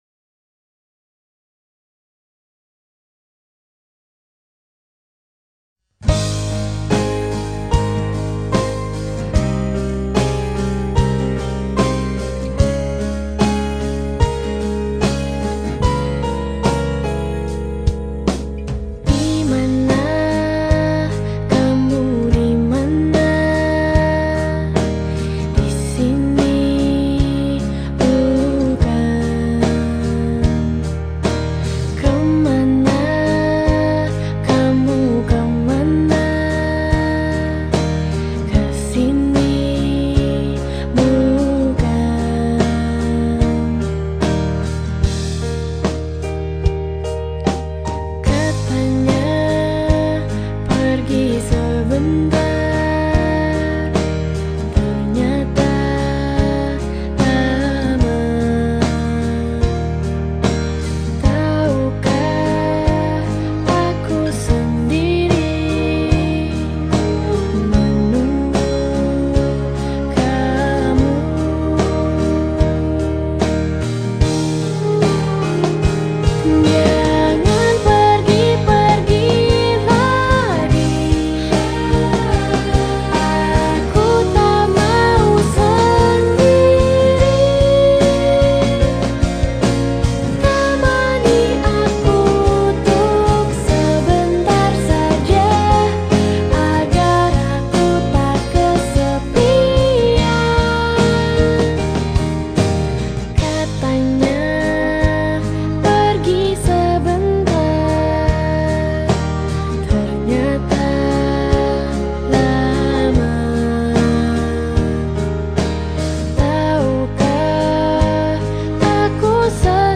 piano/keyboard
gitar
powerpop dan pop rock